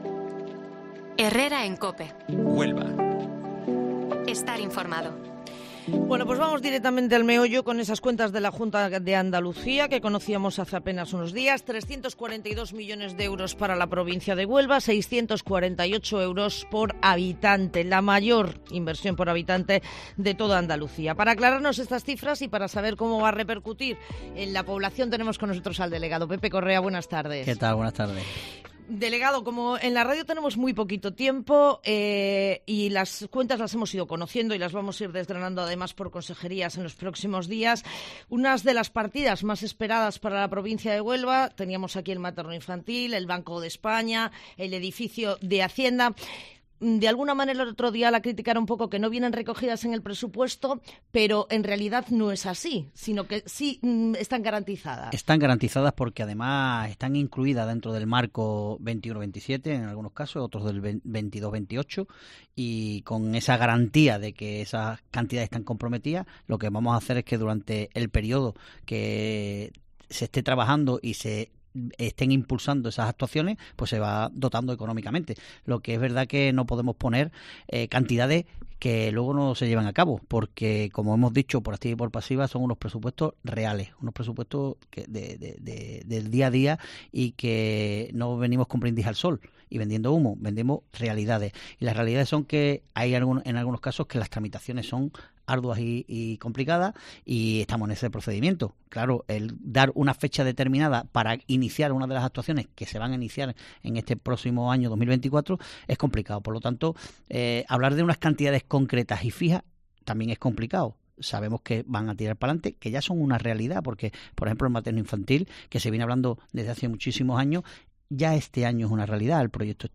AUDIO: El delegado de la Junta de Andalucía en Huelva hace un repaso de las inversiones de los nuevos presupuestos de la Junta de Andalucía